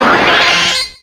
Cri de Stari dans Pokémon X et Y.